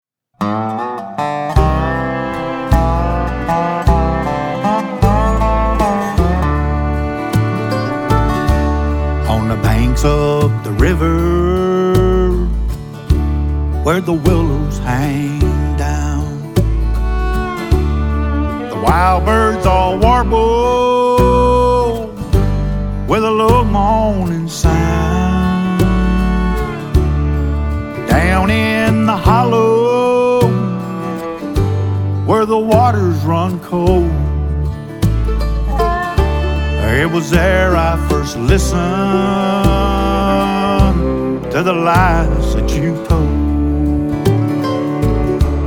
Inspired Duets, Soulful Sounds